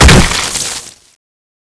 hit_world3.wav